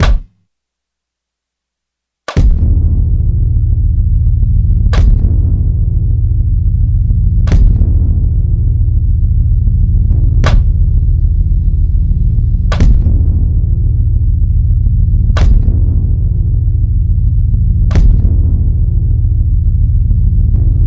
悲伤的情感钢琴 我想念你
描述：只有情绪化的钢琴。
标签： 92 bpm Hip Hop Loops Piano Loops 1.76 MB wav Key : Unknown
声道立体声